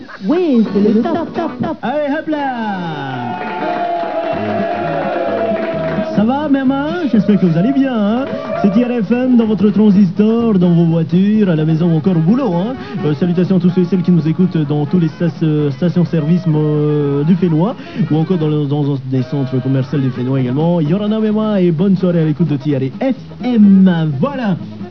mmmmmm vous êtes tous avec nouus sur Tiare FM, super ambiance... un animateur qui a gardé les vieux tics des années 80.